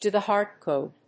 アクセントdò the [a person's] héart góod